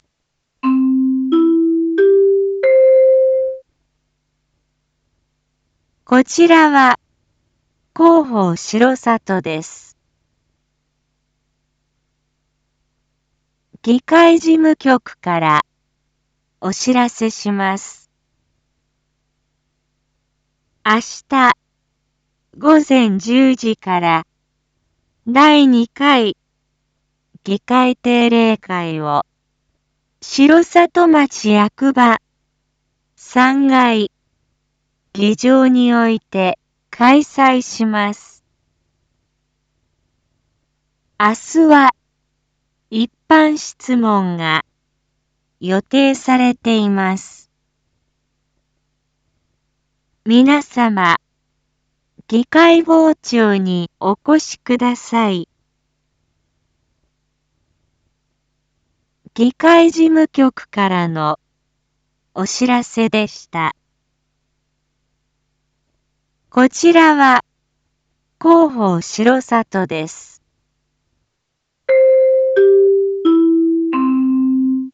Back Home 一般放送情報 音声放送 再生 一般放送情報 登録日時：2024-06-04 19:01:21 タイトル：第２回議会定例会③ インフォメーション：こちらは広報しろさとです。